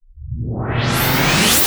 VEC3 Reverse FX
VEC3 FX Reverse 51.wav